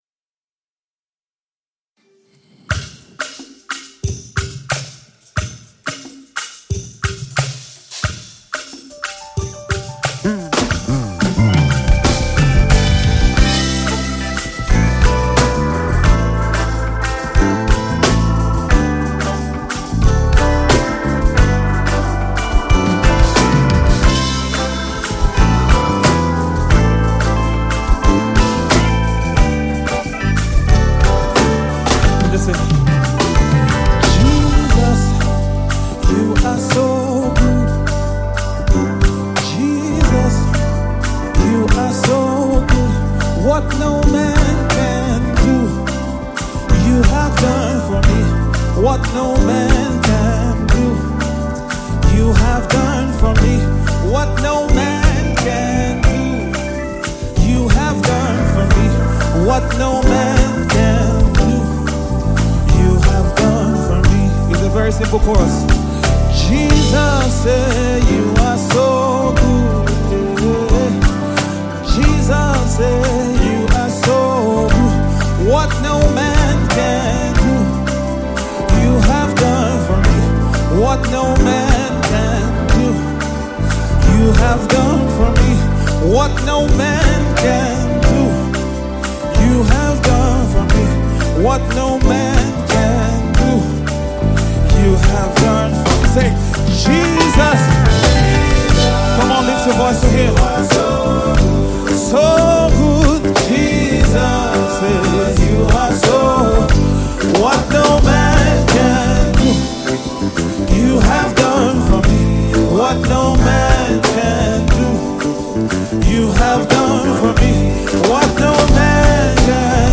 is a thanksgiving song
was recorded live video